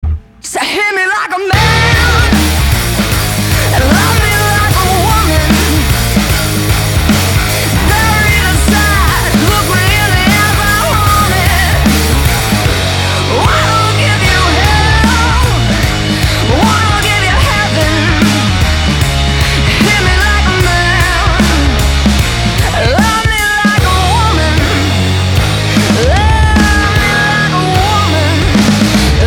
• Качество: 320, Stereo
громкие
женский вокал
Alternative Rock
энергичные
Hard rock